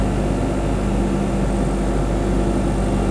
vendingmachine.wav